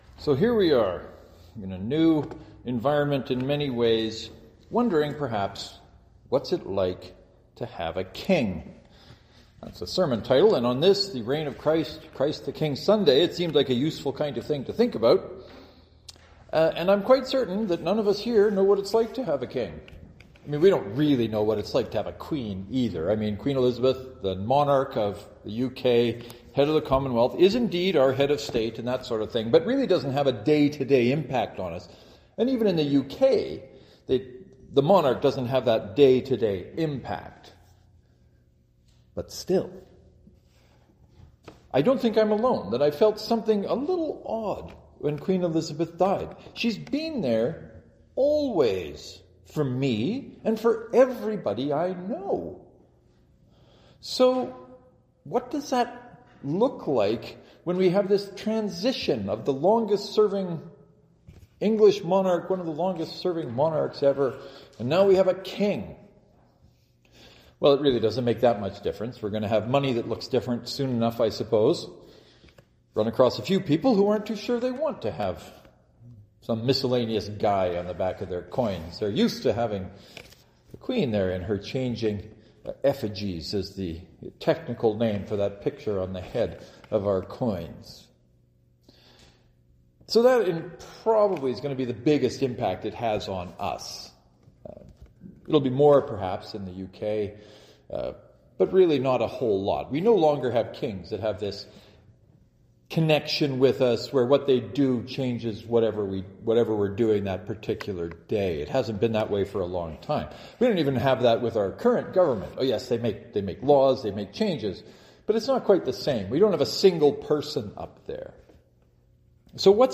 The sermons this Sunday are devoted to trying to answer the last question above.